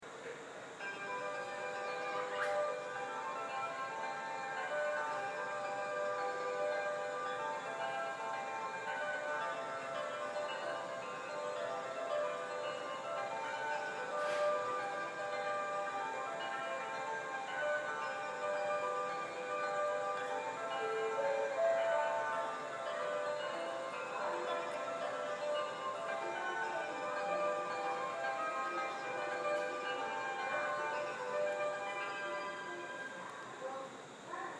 ○発車メロディー○
大宮駅とはアレンジが異なります。
スピーカーも設置されており、音質はとても 良いです。スピーカーの数はやや少なめで、音量も大きくはないです。主な収録場所はホーム端をお勧めします。基本的にフルコーラスは流れますが、場合によっては途中切 りされることもあります。
１番線普通・区間急行急行